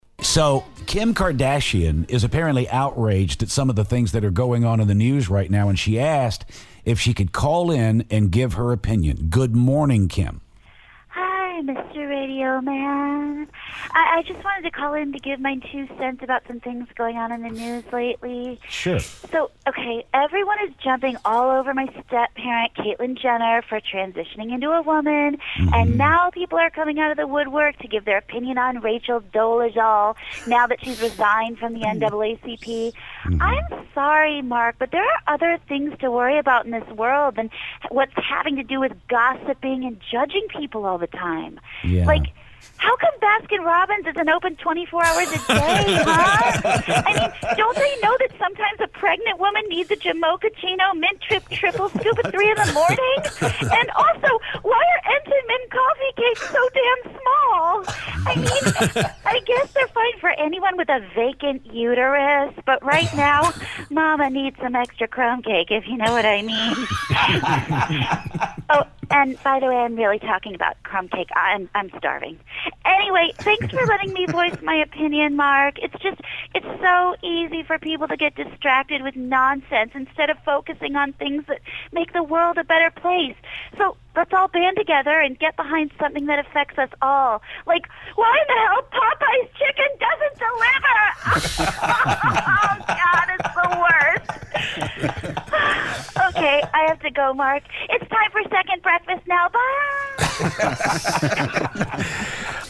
Kim K. calls the show and she's not happy!